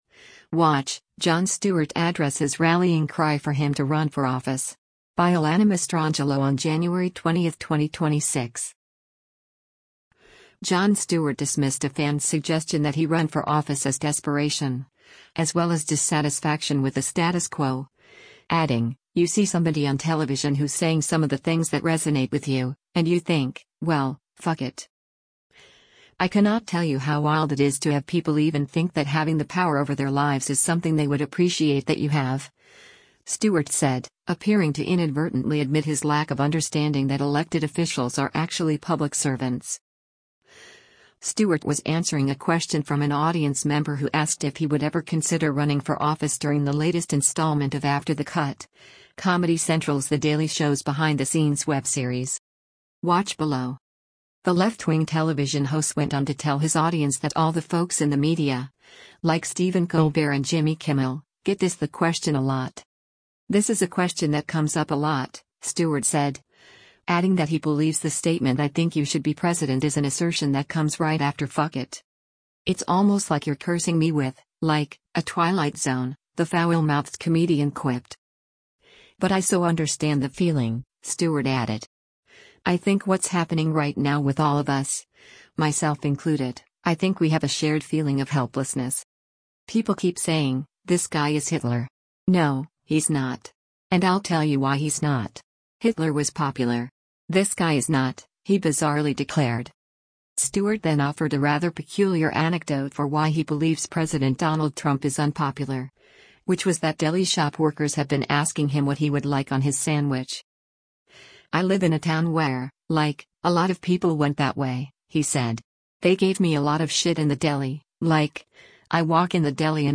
Stewart was answering a question from an audience member who asked if he would ever “consider running for office” during the latest installment of “After the Cut,” Comedy Central’s The Daily Show‘s behind-the-scenes web series.